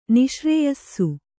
♪ niśrēyasasu